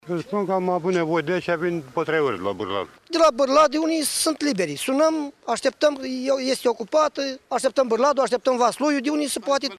Locuitorii din zona oraşului Murgeni spun că din cauza lipsei ambulanţelor, bolnavii sunt nevoiţi să aştepte ajutor de urgenţă chiar şi 24 de ore:
13-feb-rdj-17-Vox-oameni.mp3